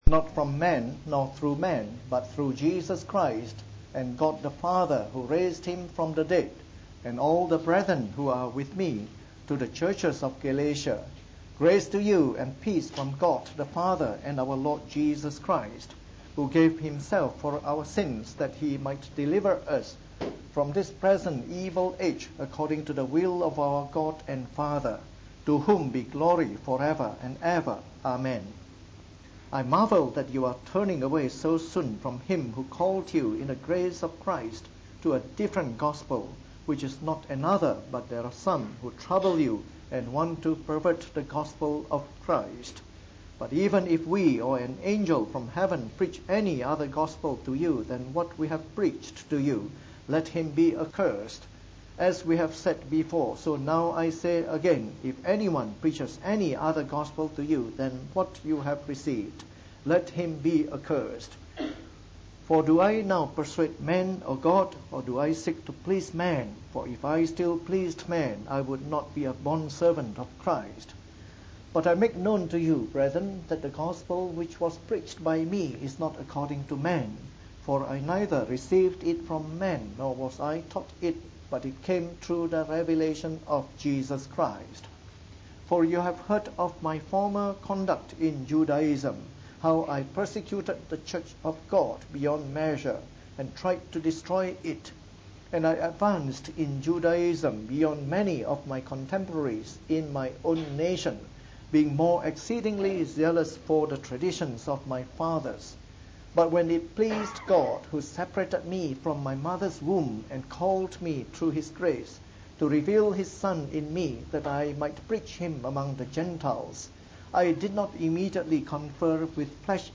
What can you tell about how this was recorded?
at a special combined evening service.